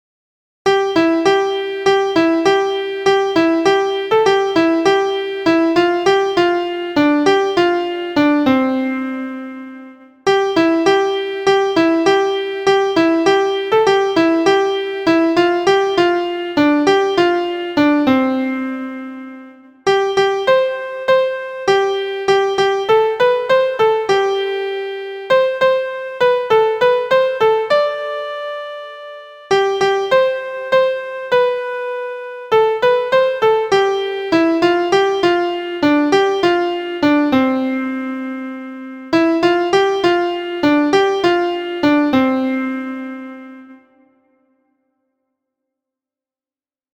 Syncopation in 2/2 (cut) time and an
• Key: C Major
• Time: 2/2 – cut time
• Form: AB